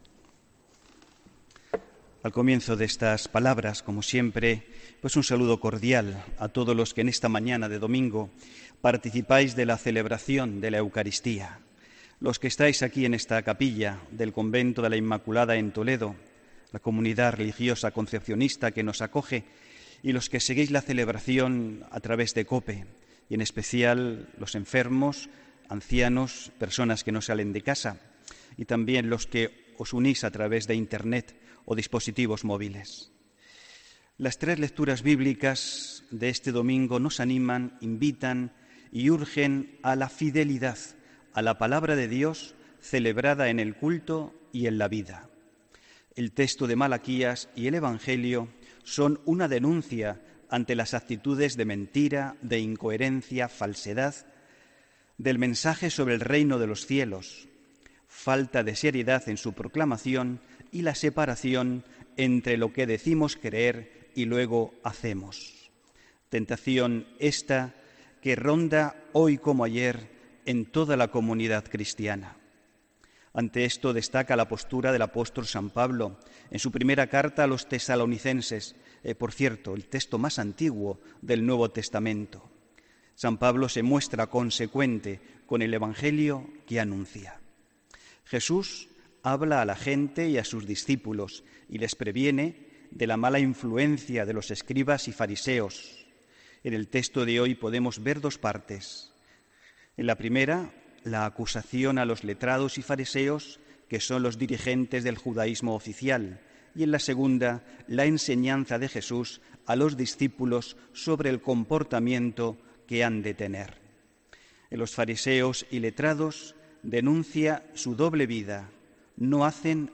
AUDIO: Homilía 5 de noviembre de 2017